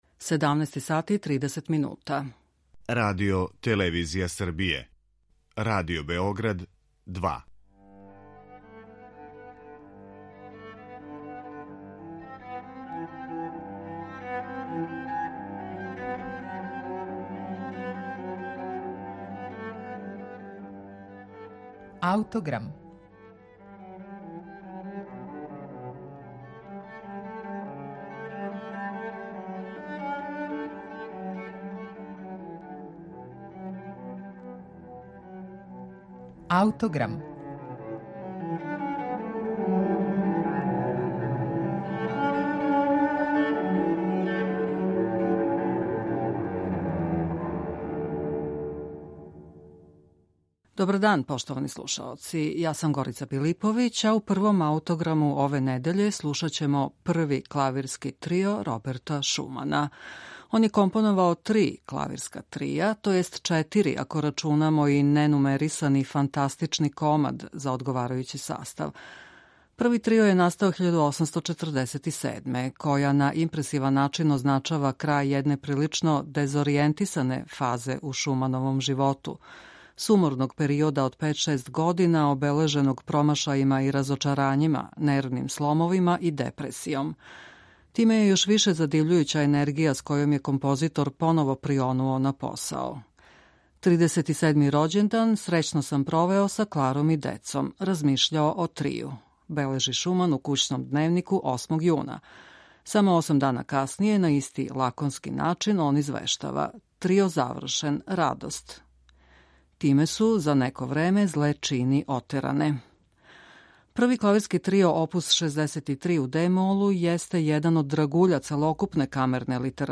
клавирски трио